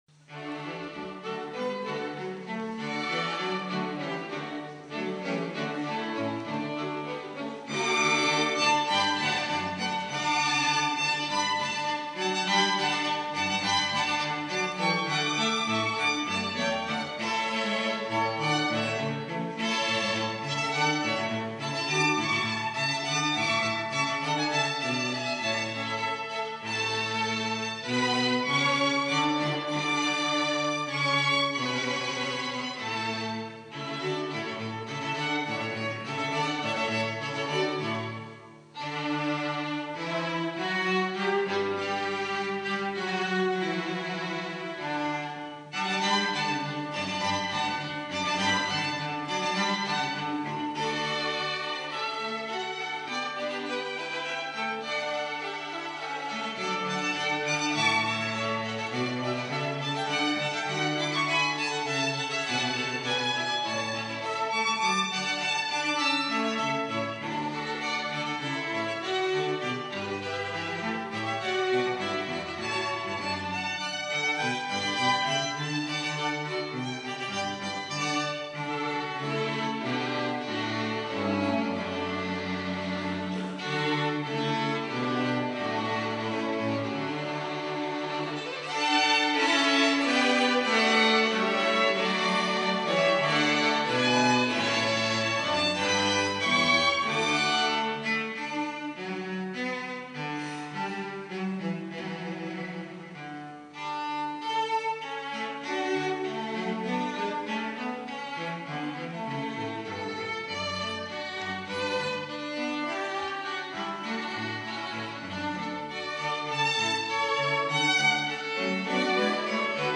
violino
viola
violoncello